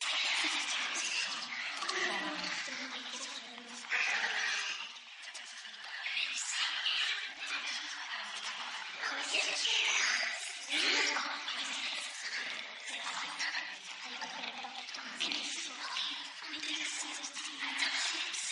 Здесь вы найдете саундтреки, фоновые шумы, скрипы, шаги и другие жуткие аудиоэффекты, создающие неповторимую атмосферу ужаса.
Звук скрытых детей - Шепот (Little Nightmares)